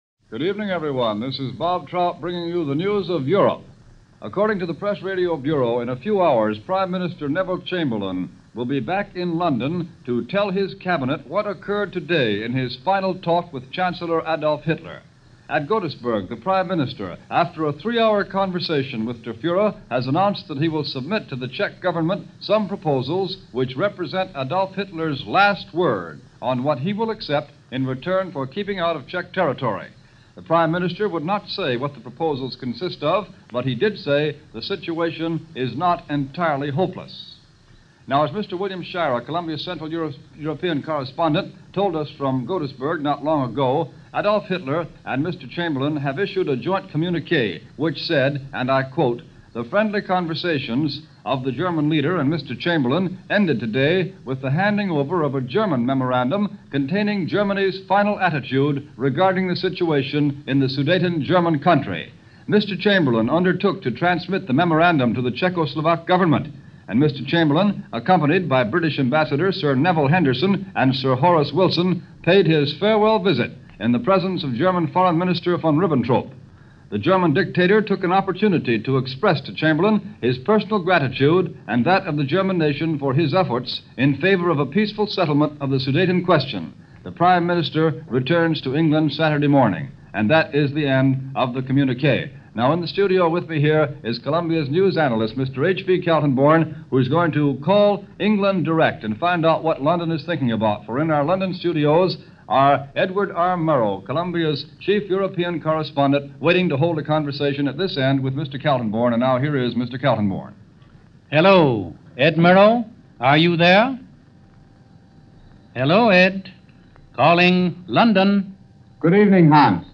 Click on the link here for Audio Player – CBS Radio News reports on Munich Crisis – September 24, 1938 […]
Here is the latest news on the crisis, as reported by CBS Radio and its around-the-clock coverage, including talks by H.V. Kaltenborn and an interview in London between Edward R. Murrow and Czech diplomat Jan Masaryk.